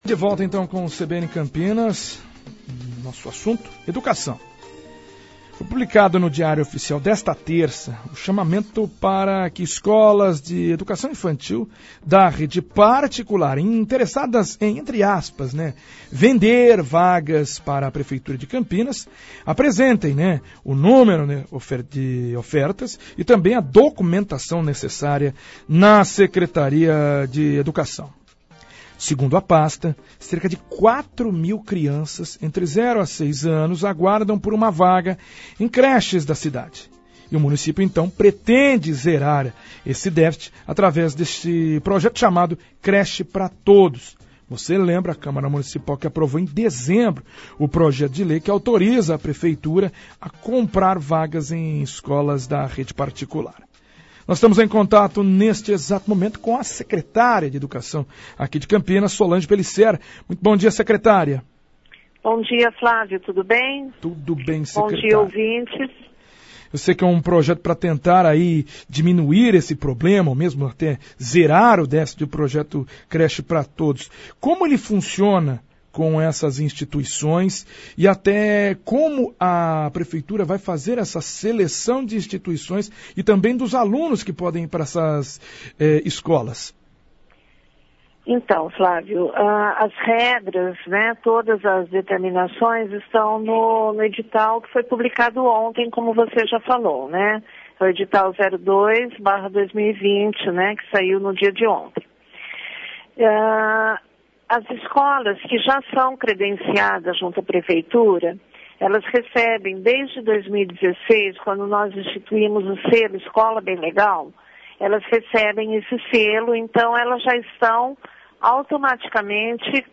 Secretária Municipal de Educação, Solange Pelicer fala sobre como será este programa aqui na cidade de Campinas